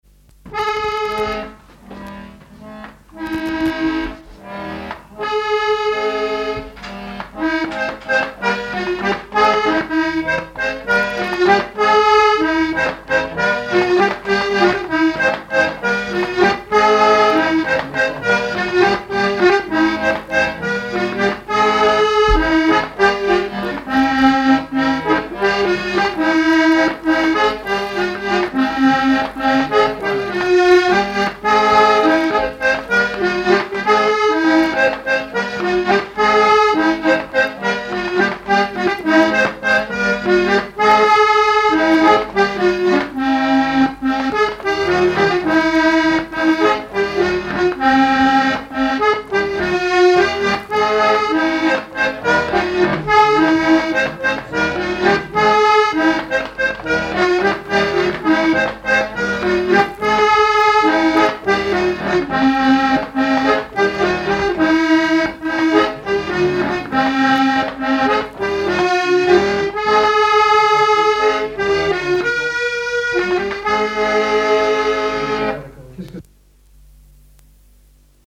Chants brefs - A danser
danse : polka
à l'accordéon diatonique
Pièce musicale inédite